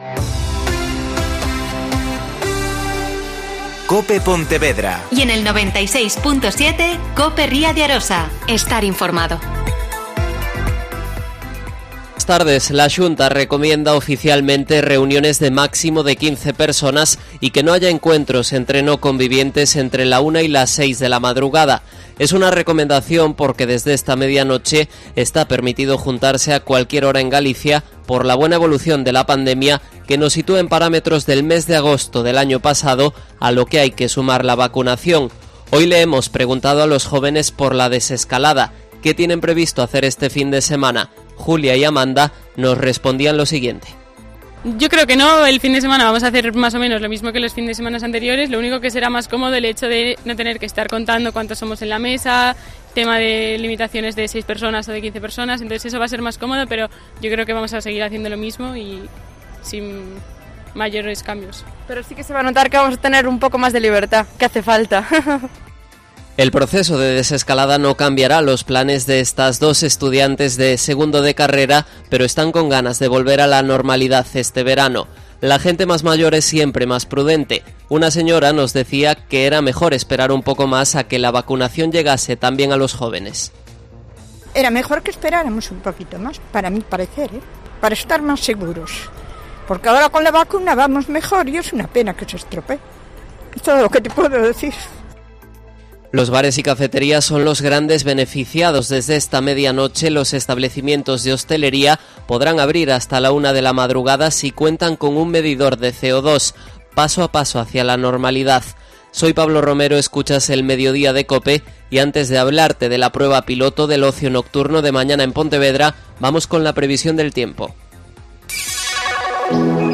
Entrevista sobre el fin de los exámenes de la ABAU en Galicia